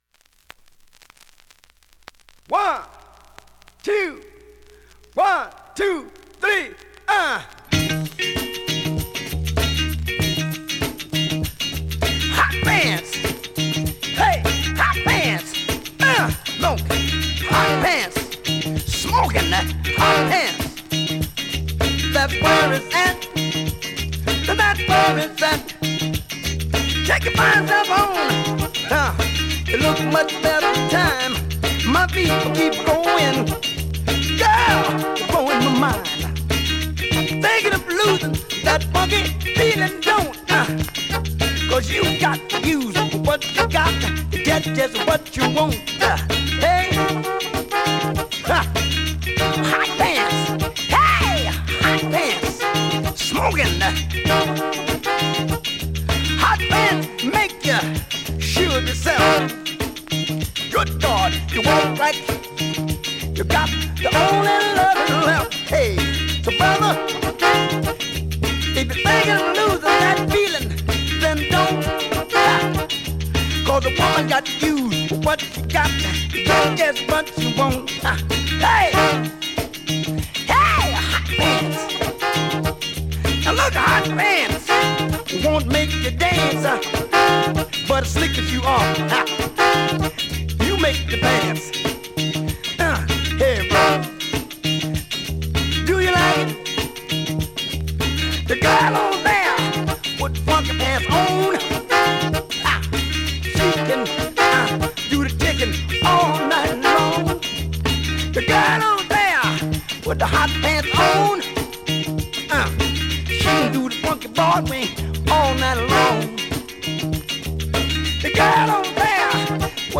◆盤質両面/VG薄いスレ多めですが普通に聴けます。